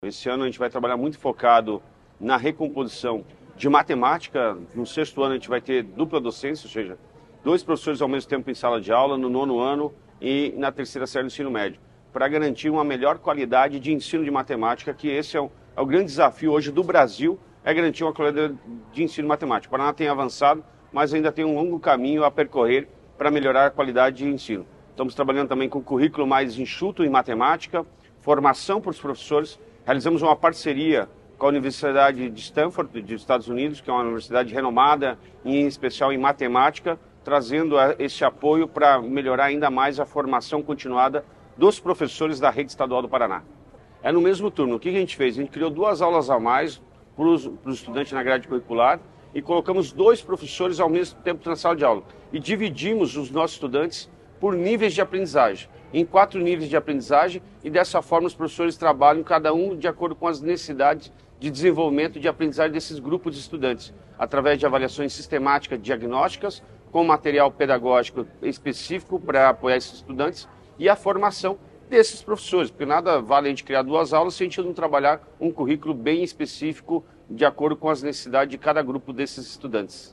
Sonora do secretário da Educação, Roni Miranda, sobre o fortalecimento do ensino de matemática na rede estadual